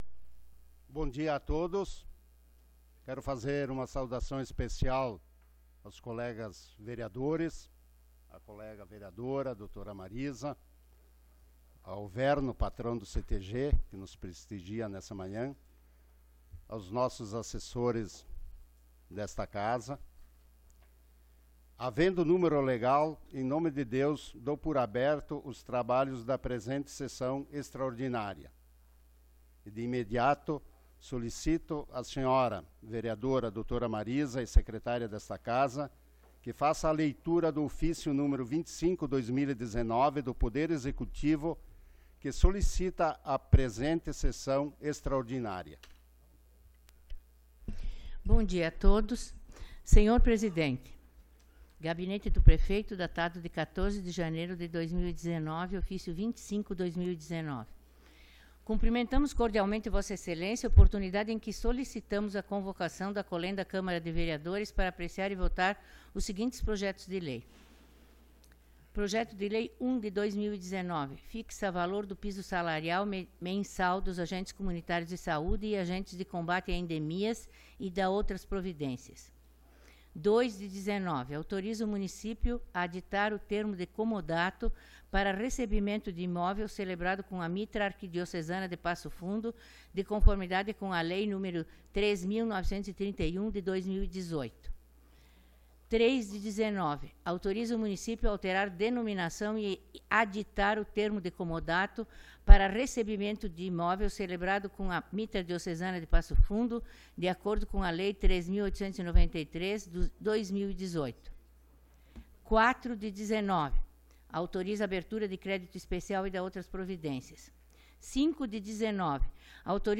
Sessão Extraordinárias do dia 21 de Janeiro de 2019